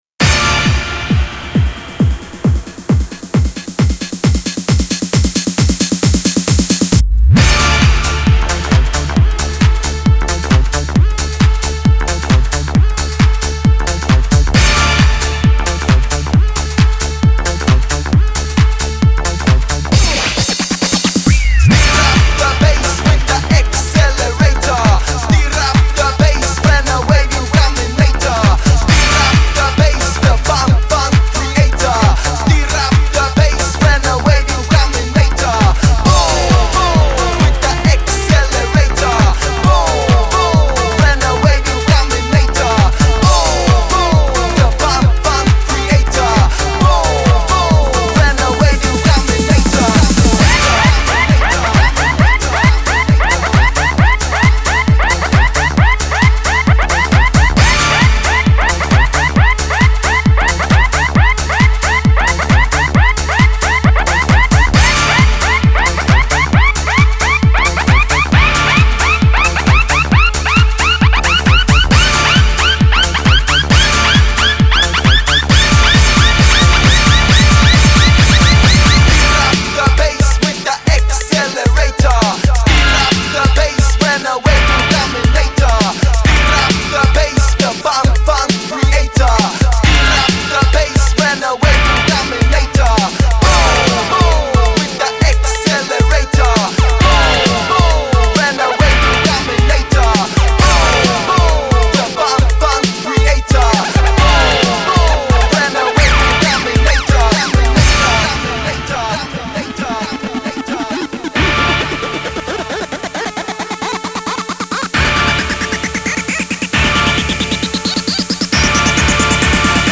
Жанр: хип-хоп-реп